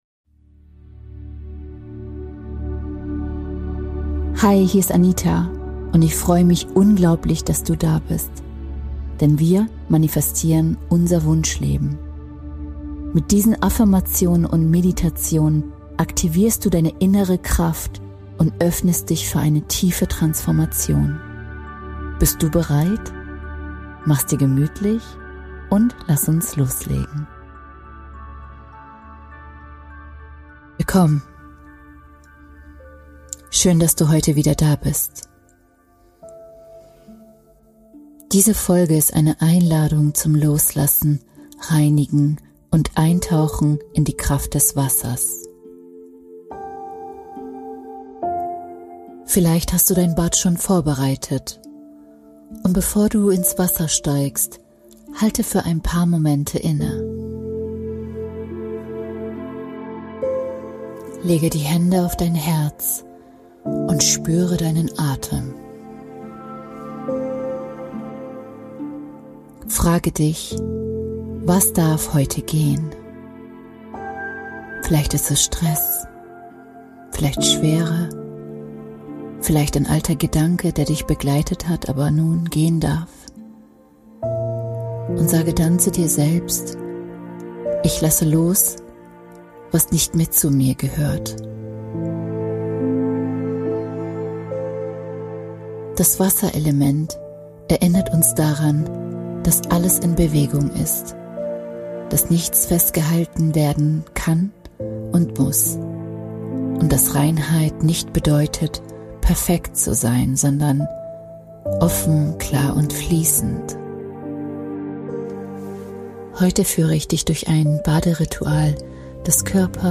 Diese geführte Meditation begleitet